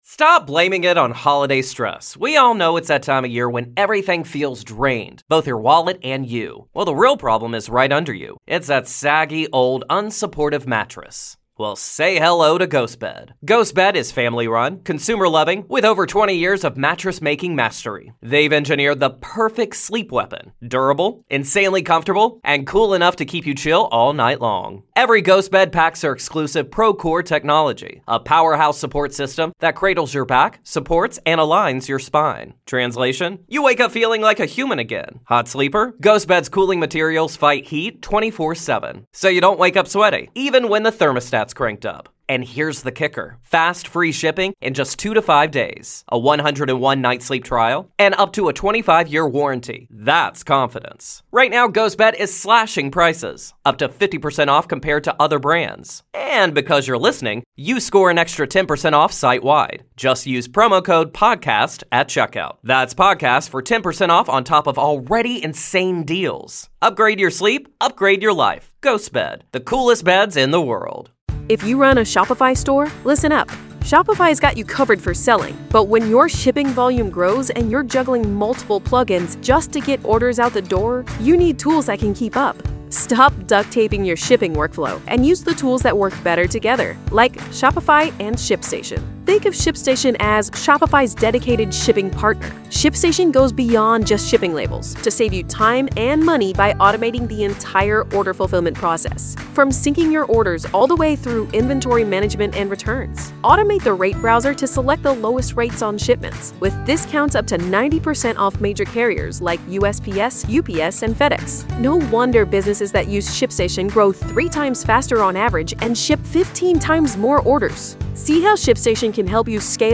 Speakers: Steve Harvey Jim Rohn Les Brown TD Jakes Joel Osteen